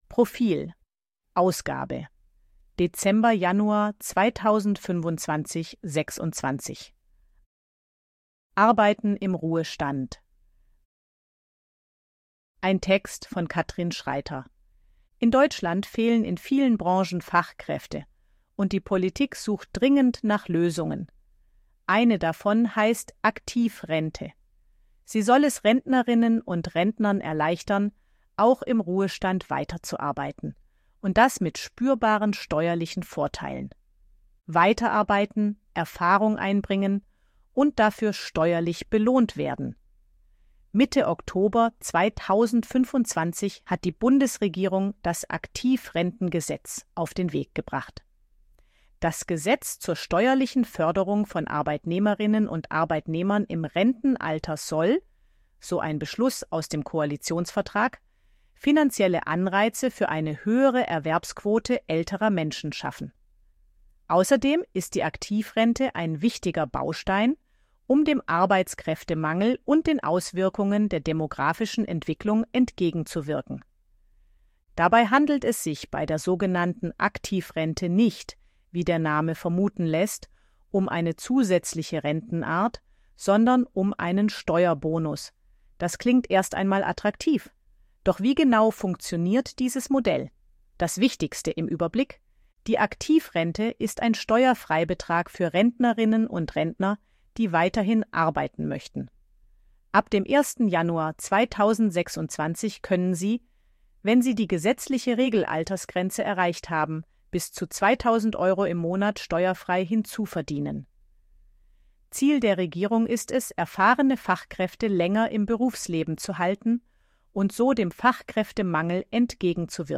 ElevenLabs_256_KI_Stimme_Frau_Service_Arbeit.ogg